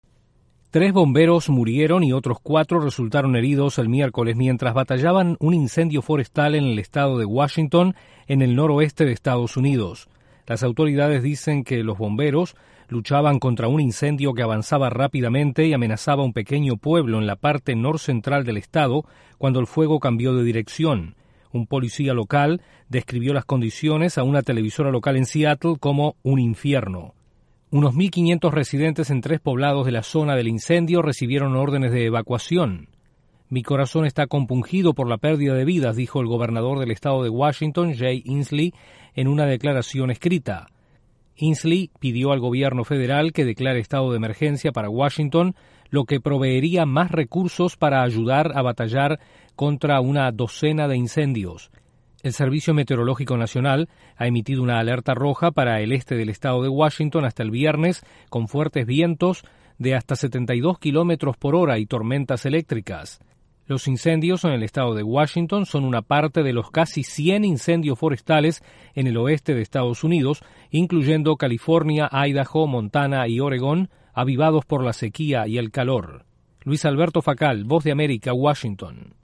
Los casi 100 incendios forestales en EE.UU. causan la muerte de tres bomberos. Desde la Voz de América en Washington informa